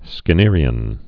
(skĭ-nîrē-ən)